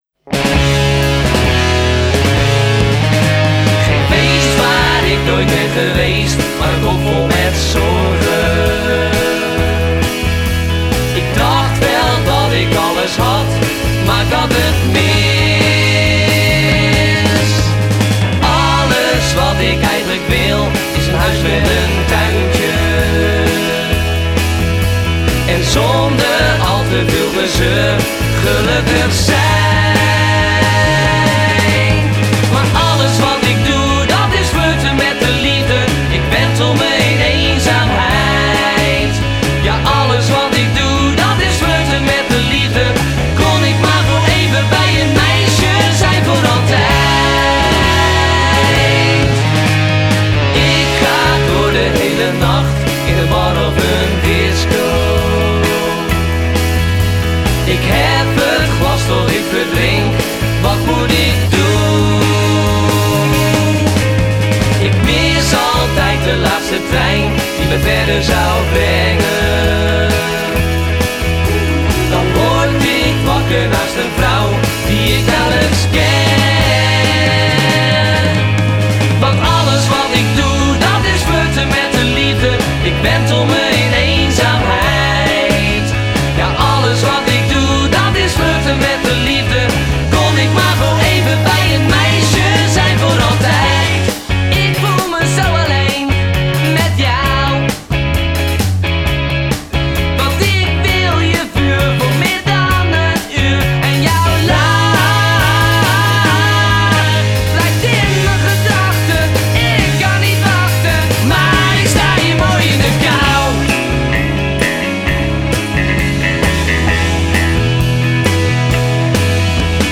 mid-1960s British beat sound